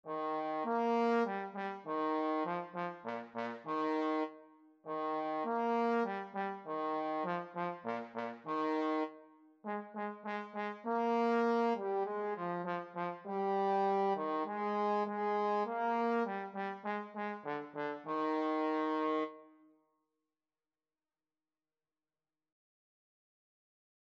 4/4 (View more 4/4 Music)
Bb3-Bb4
Moderato
Eb major (Sounding Pitch) (View more Eb major Music for Trombone )
Trombone  (View more Beginners Trombone Music)
World (View more World Trombone Music)